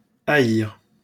wymowa:
IPA[a.iʁ] ?/i